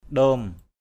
/ɗo:m/